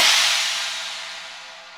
CHINA 2.wav